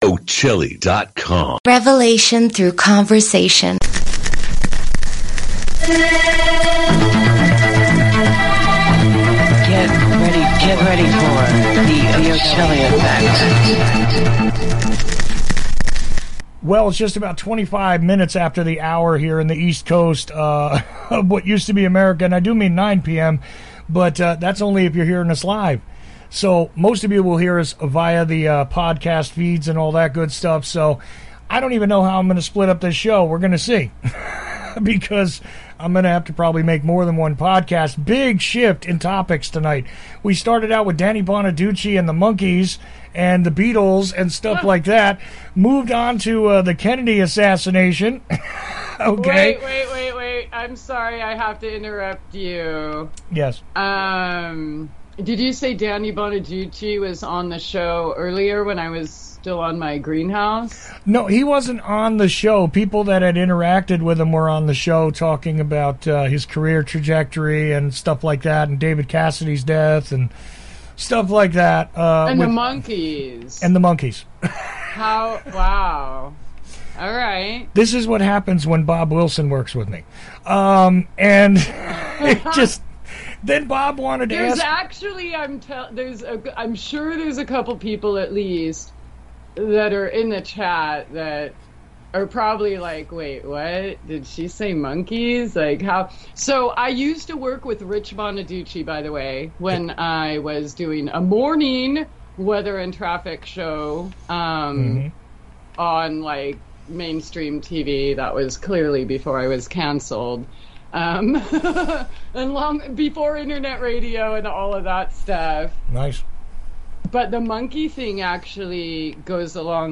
Do we gain anything by ignoring our pain? There is a frank and unscripted discussion in this podcast that is a universal concern. Pain is often a warning that something is or is about to break.